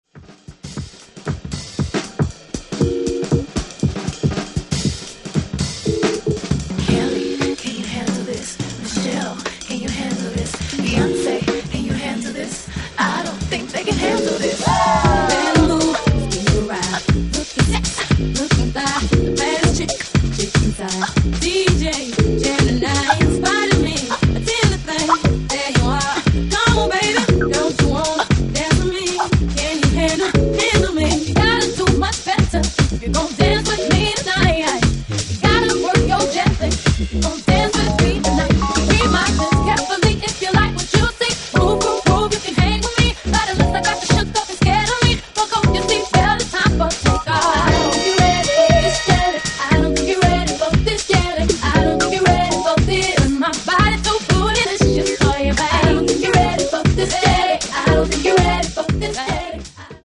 Jazz Dance Floor Re-Edits